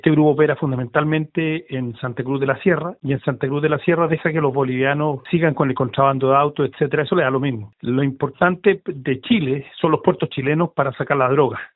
En tanto, el presidente de Amarillos, el diputado Andrés Jouannet, advirtió que el interés del Comando Vermelho en Chile se debe principalmente al uso de puertos para exportar droga.